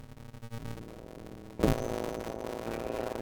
Während ich die Platte höre ist auch noch alles gut, jedoch wenn ich was gefunden habe und das Sample aufnehme und es im nachhinein anhöre ist die aufnahme voller knackser so das man es nicht mehr verwenden kann. Ich habe ein Beispiel mal exportiert damit ihr das Problem vielleicht besser zuordnen könnt.